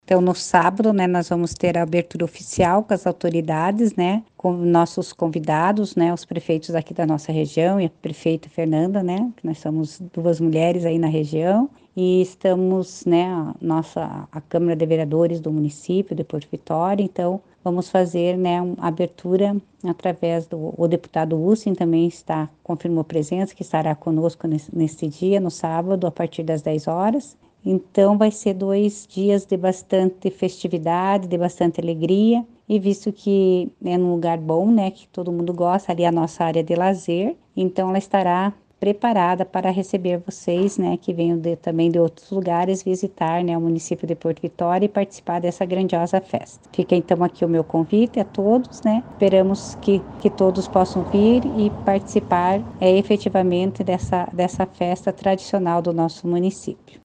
A prefeita Marisa Ilkiu convida a população e fala sobre as atrações.